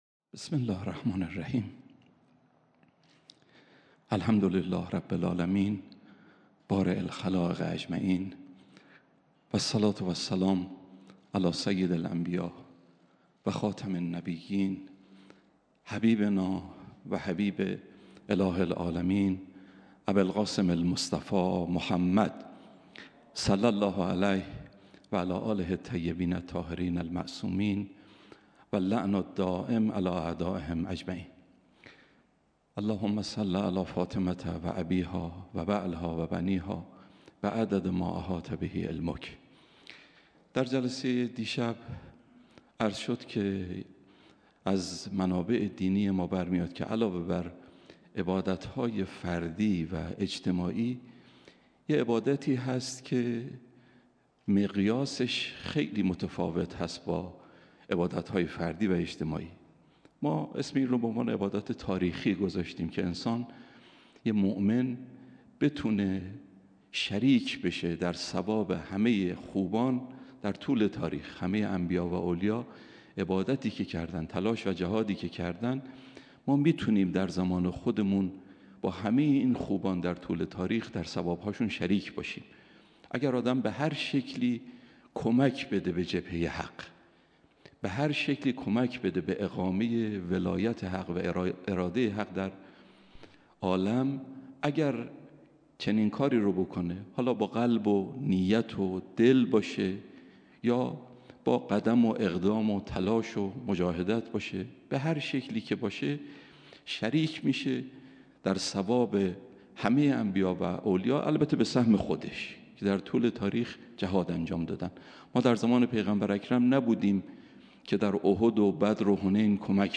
آخرین شب مراسم عزاداری شهادت حضرت فاطمه زهرا سلام‌الله‌علیها
سخنرانی